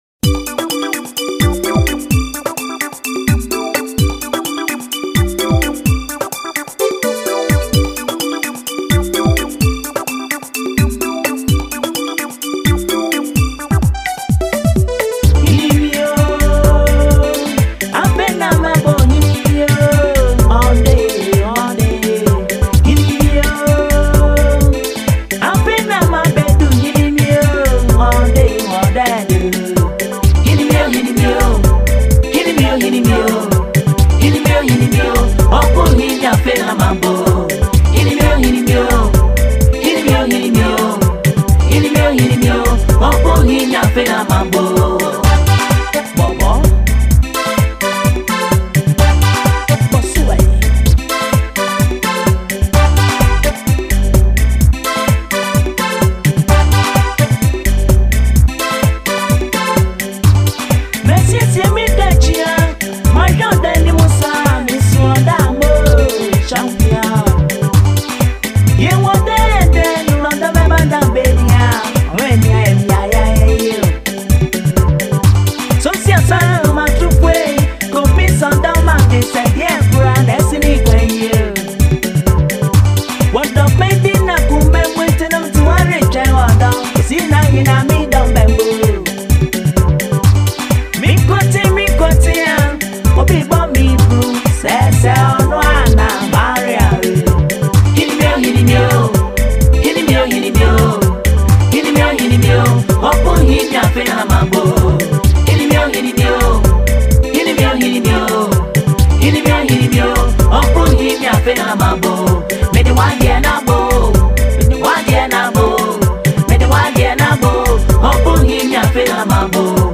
highlife
golden voice